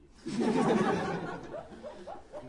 笑声 " 群笑3
描述：一群人在笑。
声道立体声